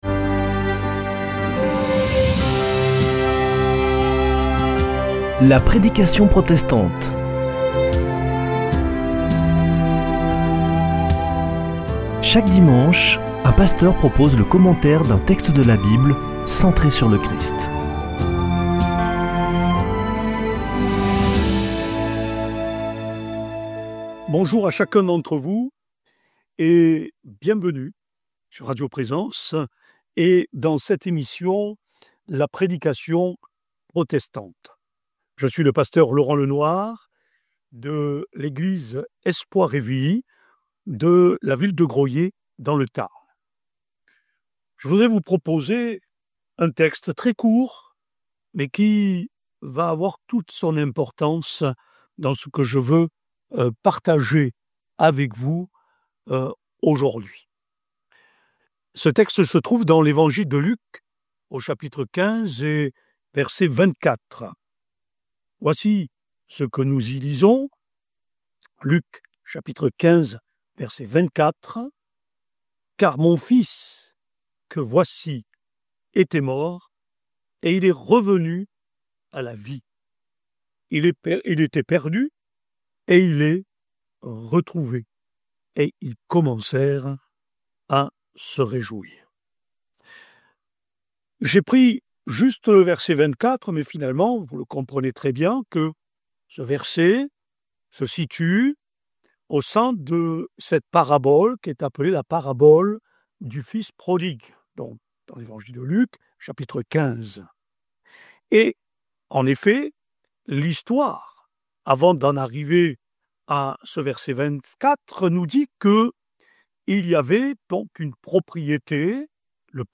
La prédication protestante
Une émission présentée par Des protestants de la région